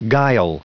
Prononciation du mot guile en anglais (fichier audio)
Prononciation du mot : guile